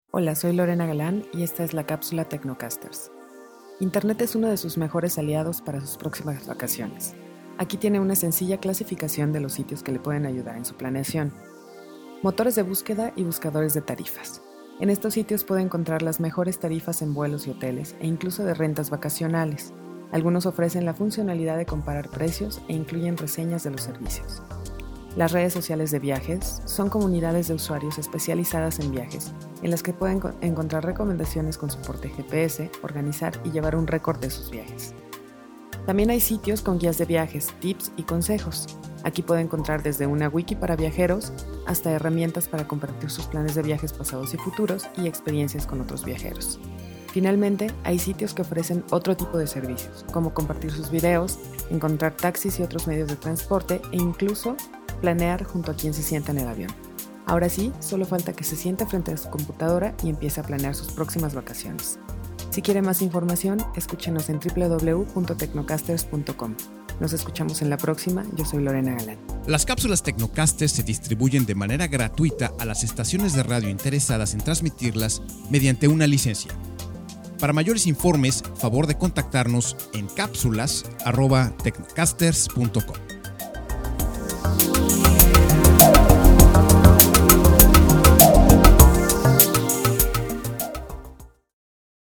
- Capsula Para transmision en Radio